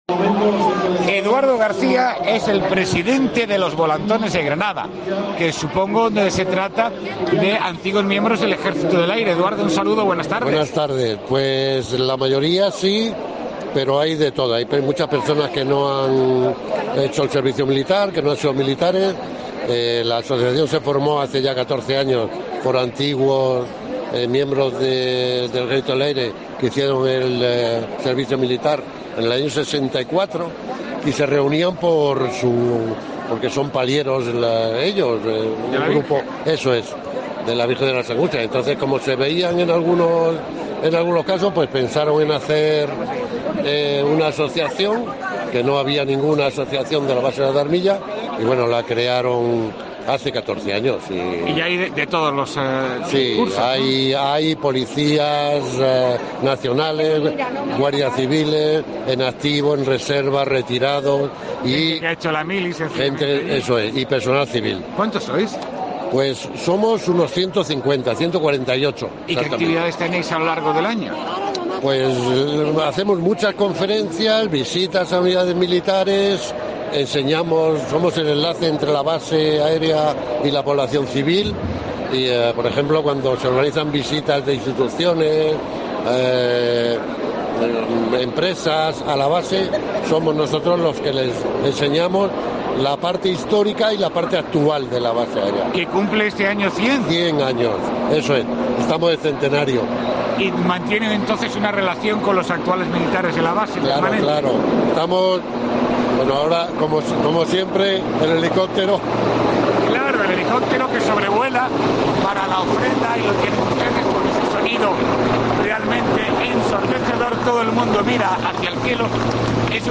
Entrevista con los Volantones del Ejército del Aire